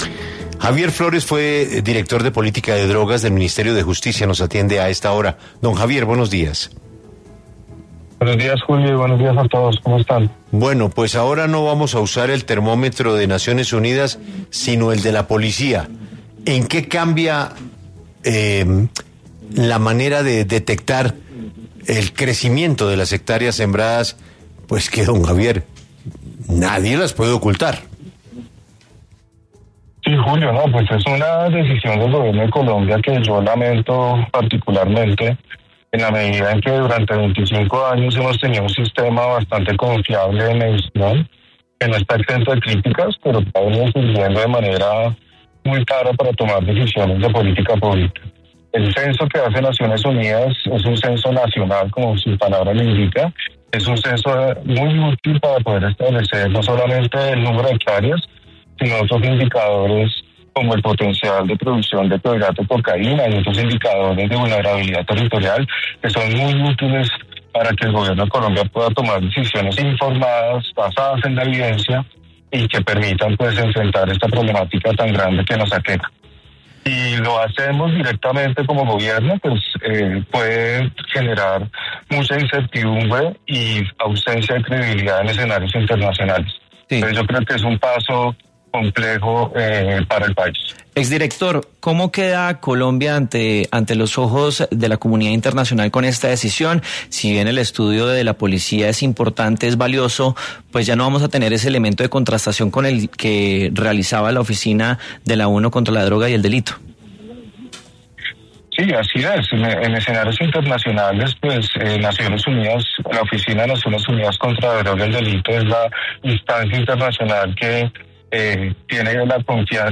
En entrevista con 6AMW